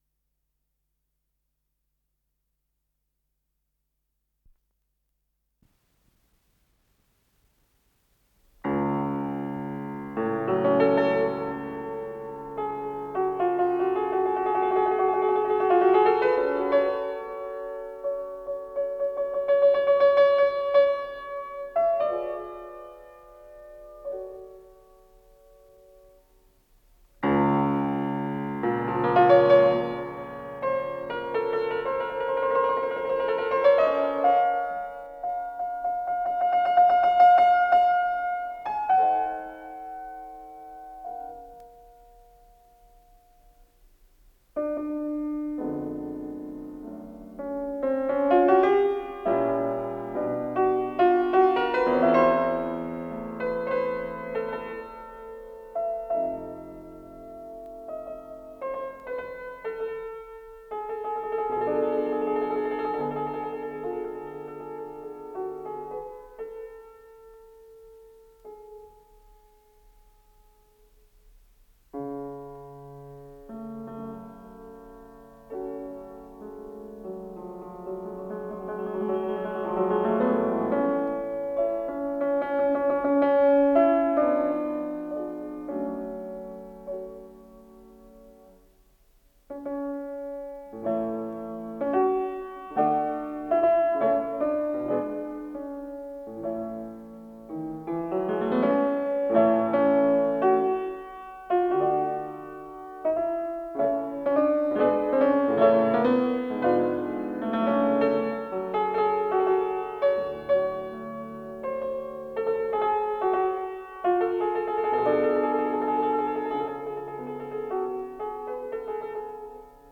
с профессиональной магнитной ленты
ПодзаголовокКаприччио для фортепиано, фа диез мажор
ВариантДубль моно